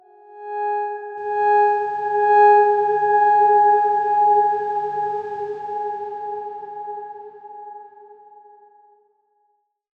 X_Darkswarm-G#4-mf.wav